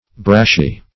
Brashy \Brash"y\, a.